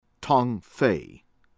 SU TSENG-CHANG SOO   (t)SUHNG    CHAHNG